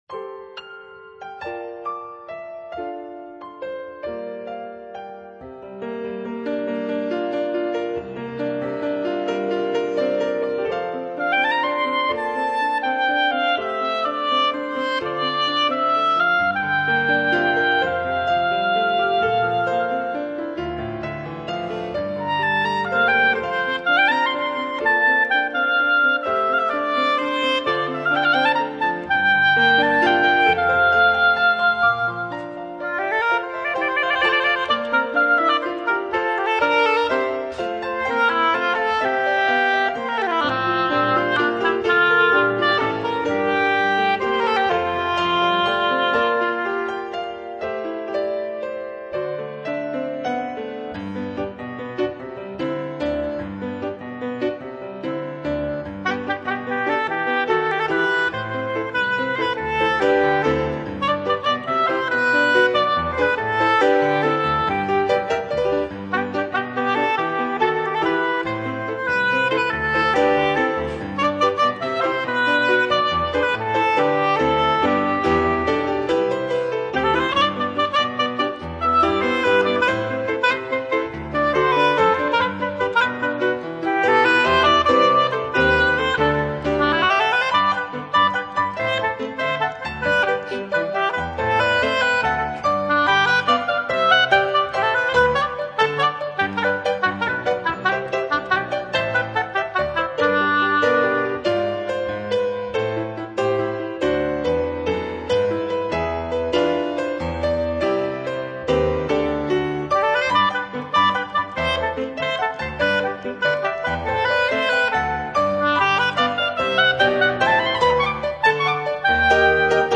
Voicing: Oboe and Piano